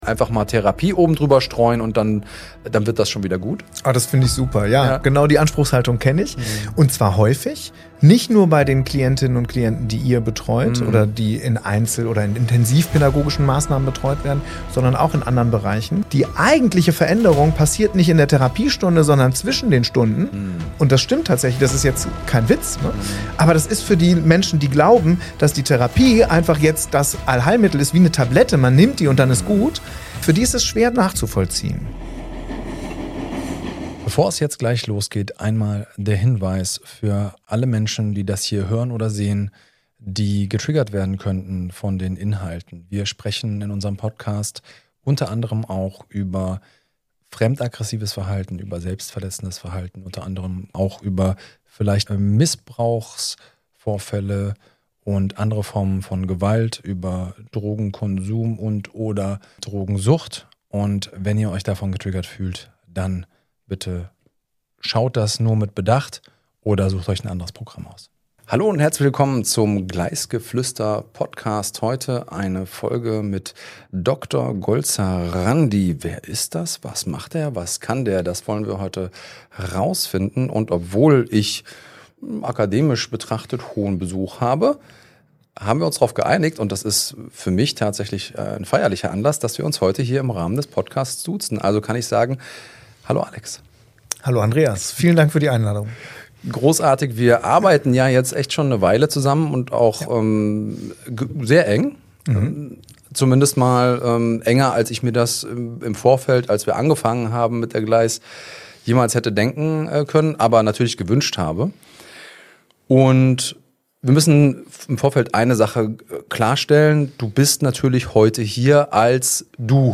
Ein ehrliches und tiefgehendes Gespräch für Fachkräfte aus Jugendhilfe, Pädagogik, Psychiatrie und Jugendämtern sowie für alle, die verstehen wollen, warum einfache Lösungen bei komplexen Biografien nicht funktionieren.